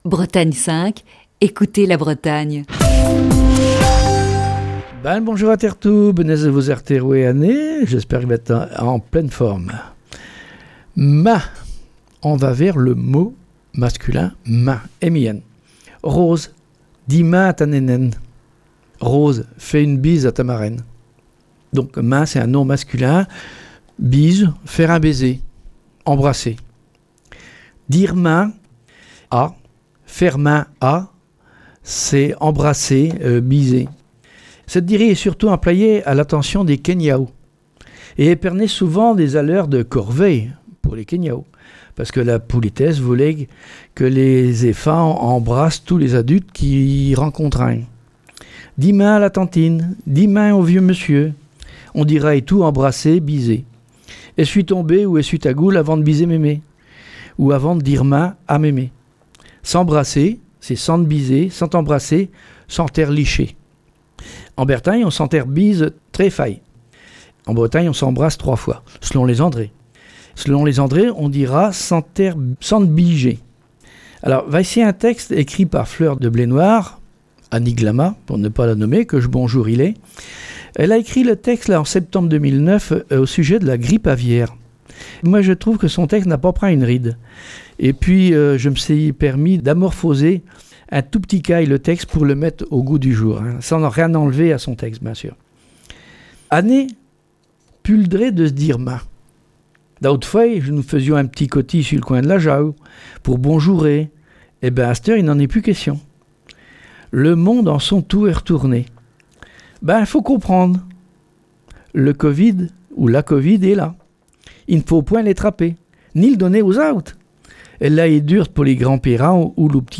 Chronique du 4 novembre 2020.